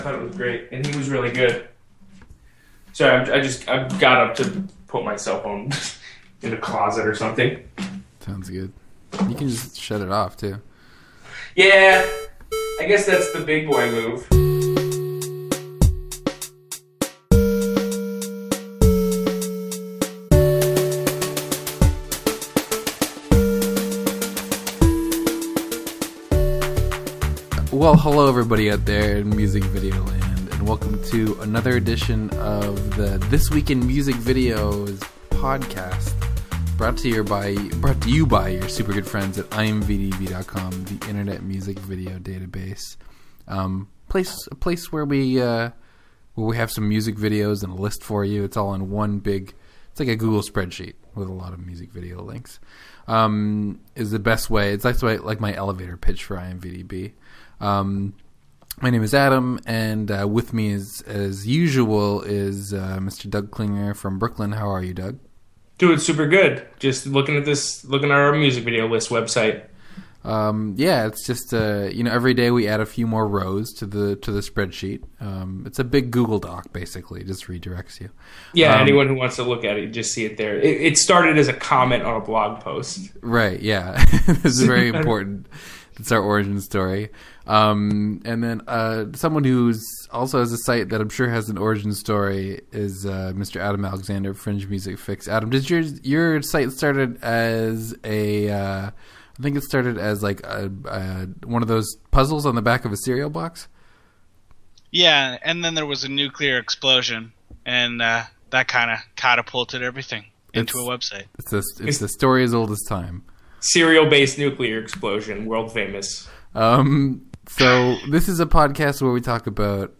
A weekly conversation about music video news and new releases.